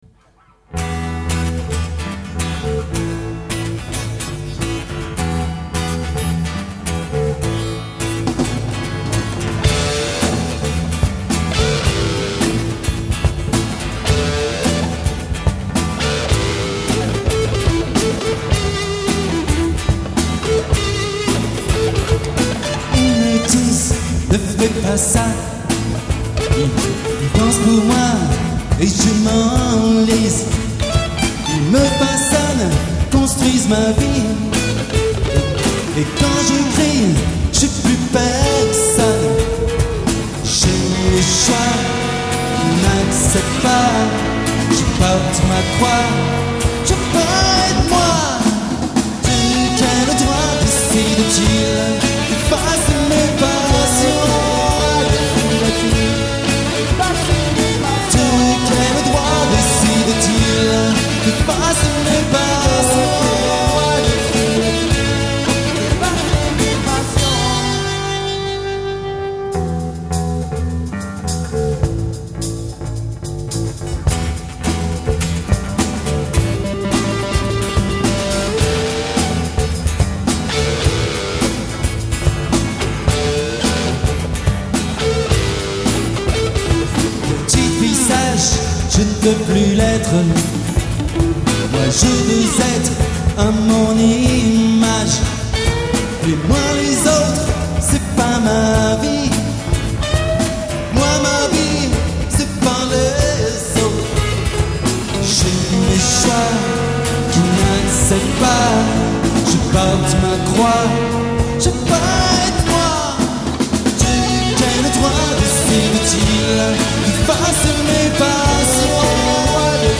Batterie
Basse
Lead guitare
Folk et chant